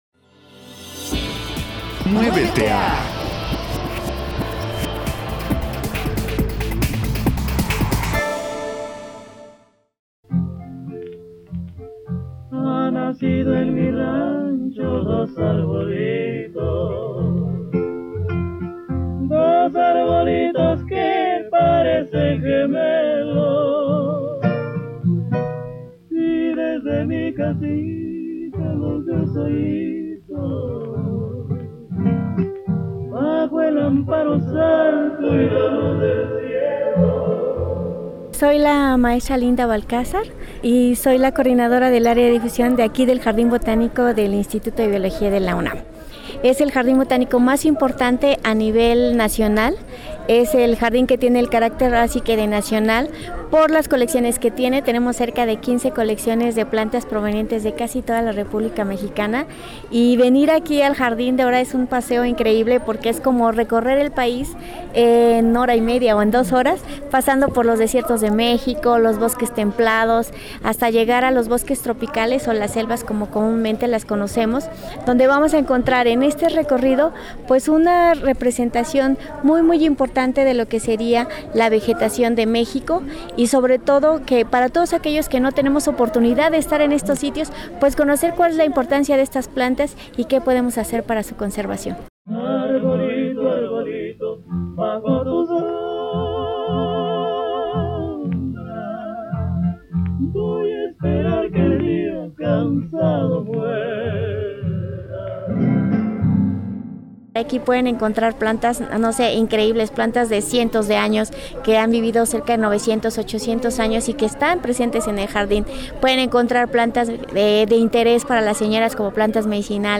El Jardín Botánico del Instituto de Biología de la UNAM nos va a ayudar a responder estas y otras preguntas en una fiesta maravillosa que tendrá lugar el próximo sábado 5 de abril con motivo del Día Nacional de los Jardines Botánicos. Les invitamos a escuchar la plática que tuvimos hace unos días